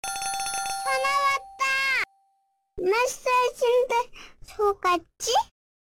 알림음 8_전화왔떠속았지.ogg